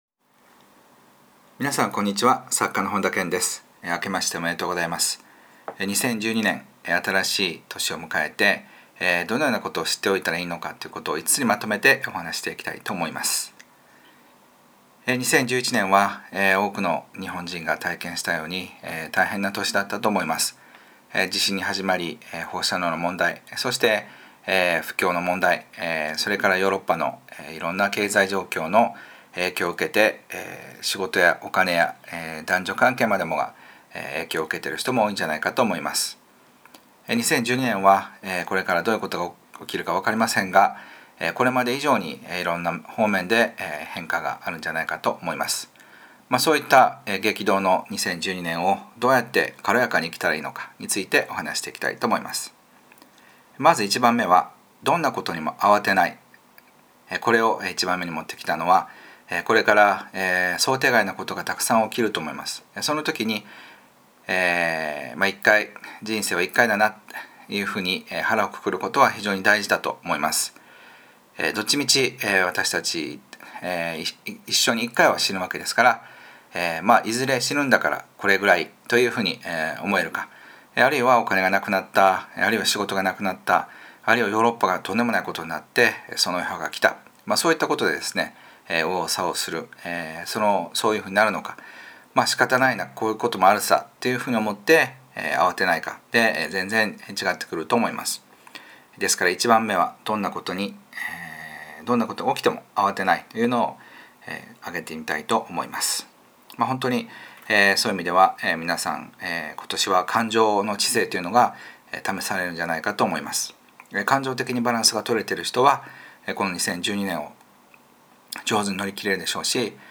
２０１２年スタートにあたり、新年の音声メッセー ジ「２０１２年を楽しく生きるために知っておきたい５つのこと」を収録 しましたので、ぜひ聞いてくださいね。